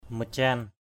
/mə-tia:n/